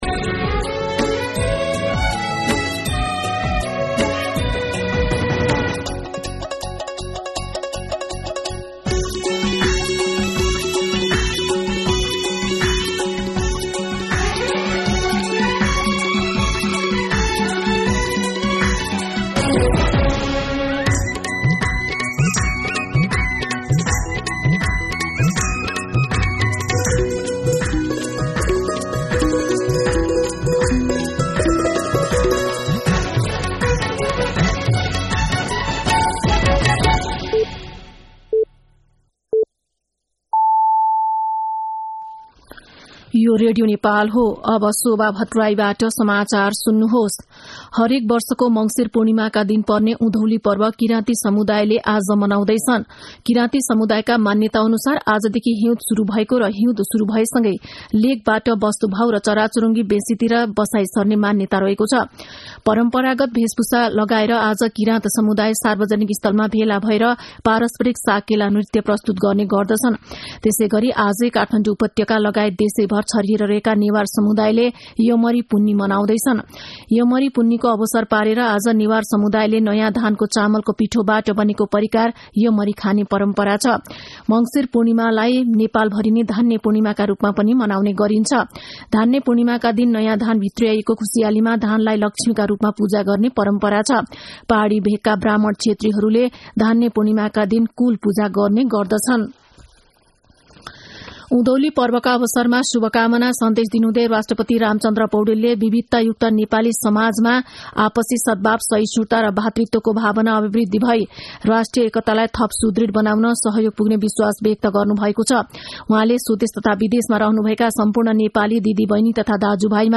मध्यान्ह १२ बजेको नेपाली समाचार : १ पुष , २०८१
12pm-Nepali-News.mp3